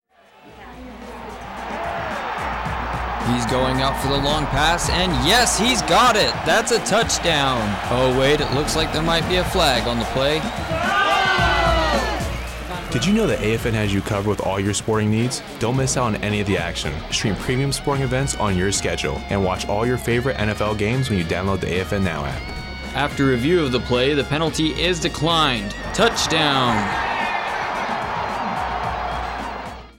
Radio spot promotes American Forces Network Now app and the National Football League.